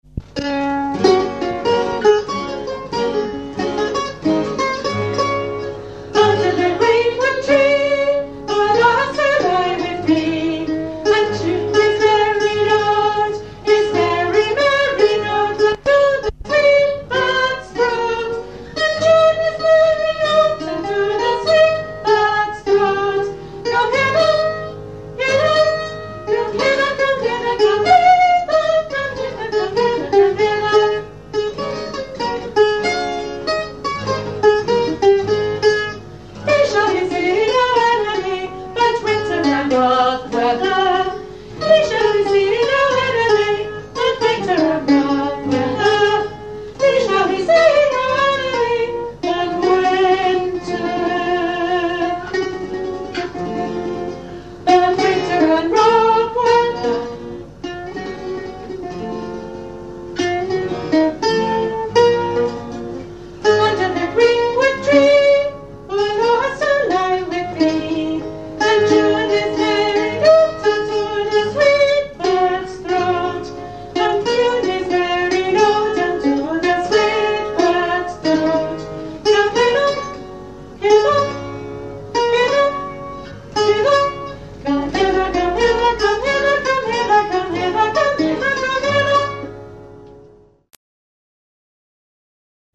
Hillbark Players - Bringing open-air Shakespeare to the Wirral